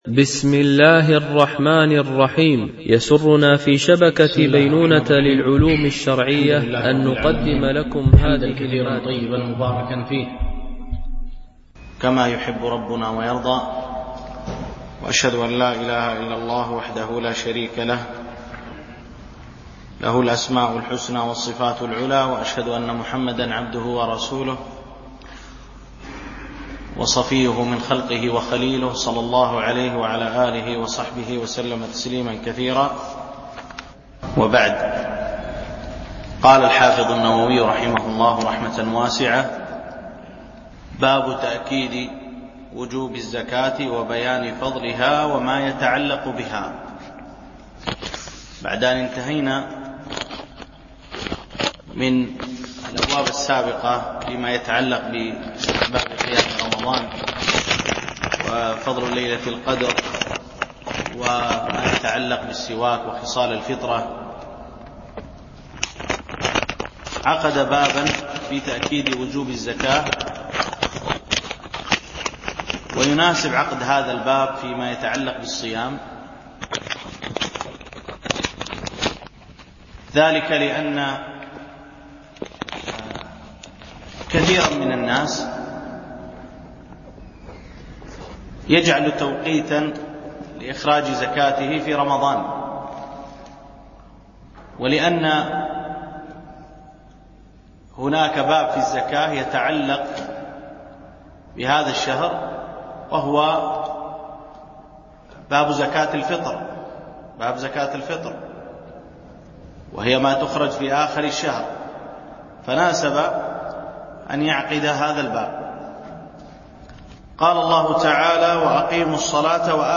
شرح أبواب الصيام من كتاب رياض الصالحين - الدرس 2